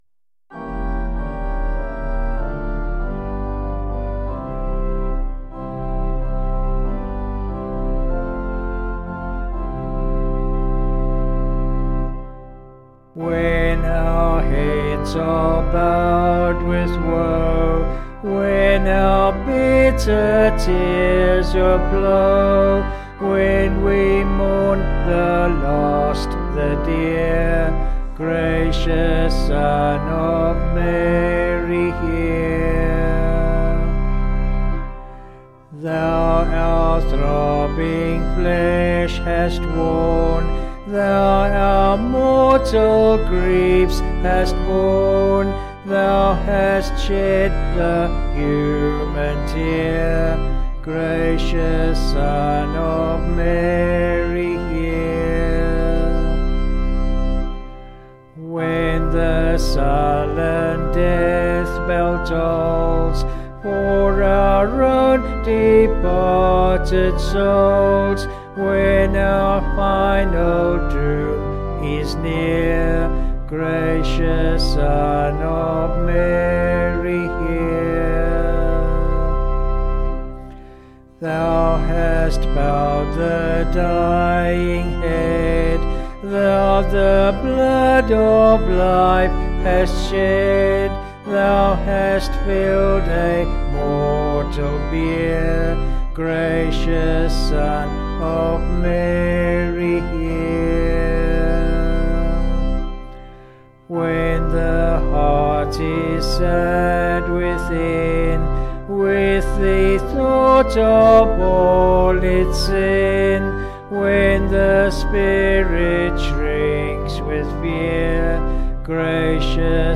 Vocals and Organ   263.4kb